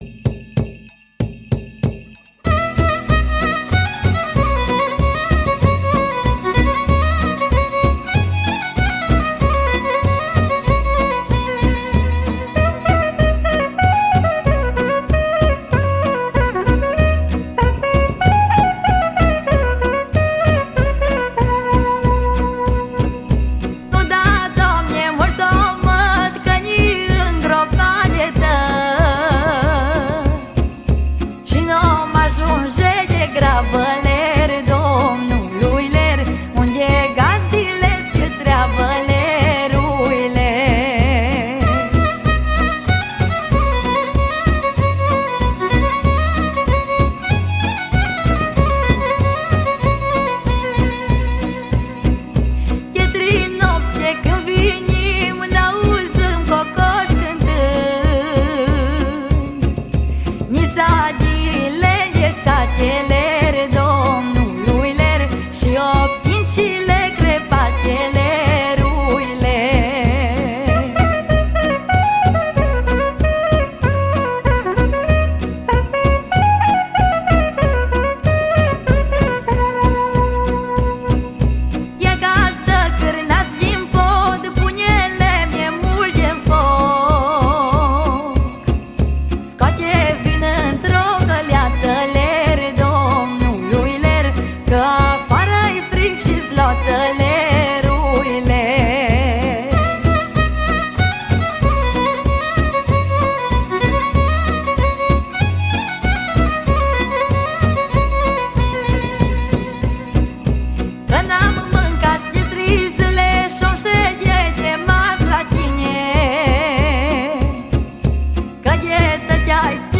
Colinde de Craciun